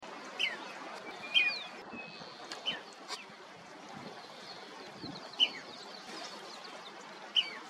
Fiofío Silbón (Elaenia albiceps)
Nombre en inglés: White-crested Elaenia
Fase de la vida: Adulto
Localidad o área protegida: Reserva Provincial Parque Luro
Condición: Silvestre
Certeza: Vocalización Grabada